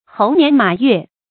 猴年馬月 注音： ㄏㄡˊ ㄋㄧㄢˊ ㄇㄚˇ ㄩㄝˋ 讀音讀法： 意思解釋： 猴、馬：十二生肖之一。